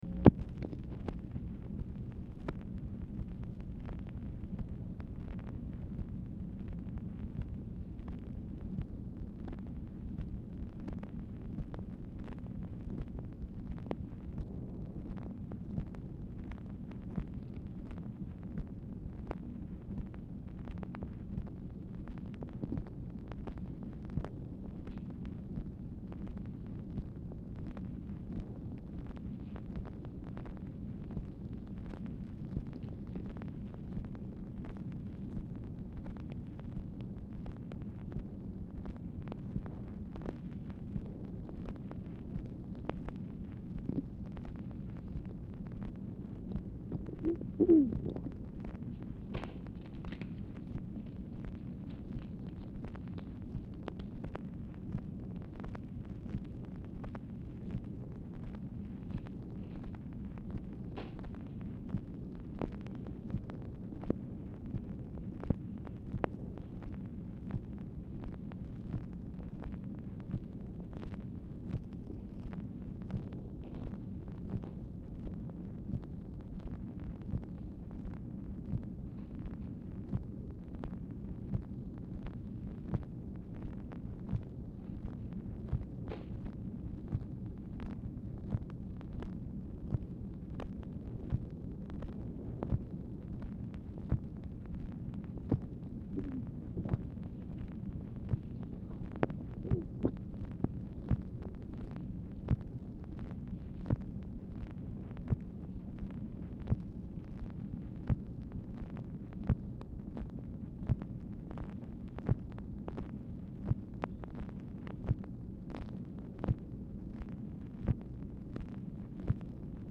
OFFICE NOISE
Format Dictation belt
Oval Office or unknown location